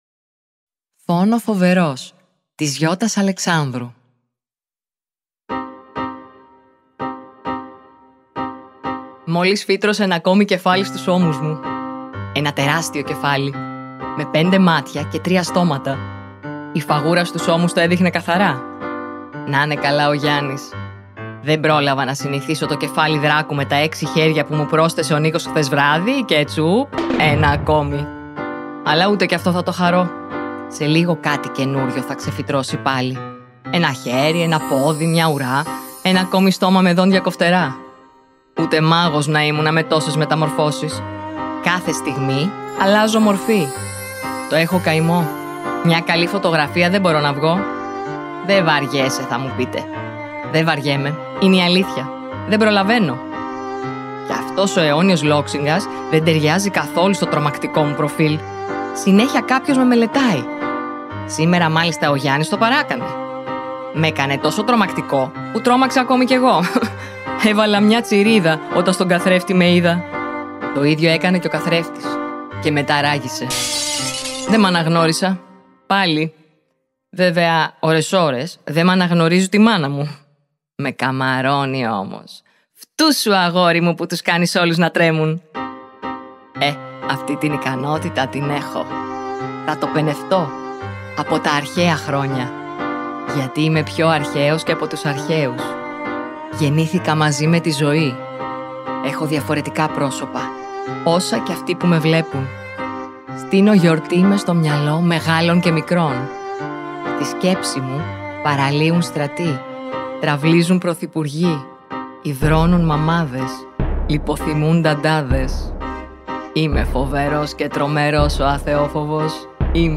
Περιέχει QR CODE με την αφήγηση
της ιστορίας από τη συγγραφέα